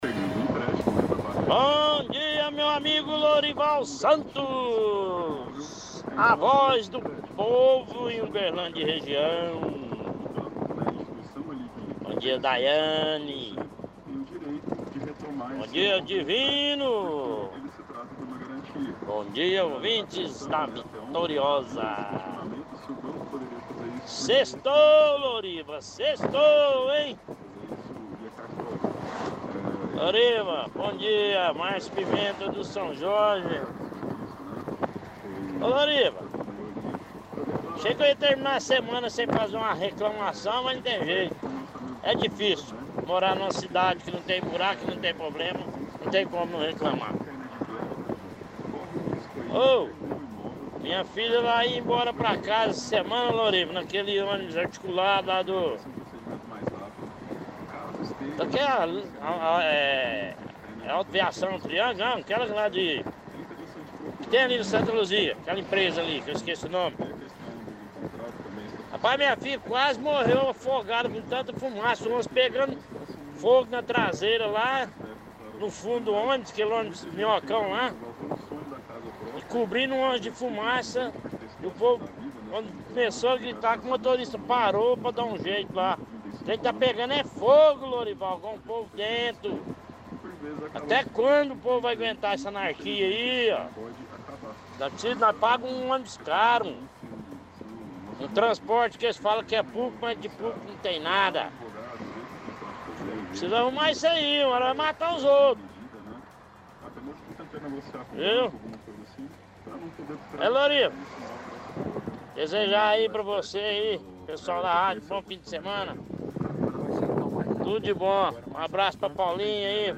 – Ouvinte reclama que sua filha estava voltando do trabalho em um ônibus articulado e ela quase morreu asfixiada porque pegou fogo dentro do ônibus e tinha muita fumaça.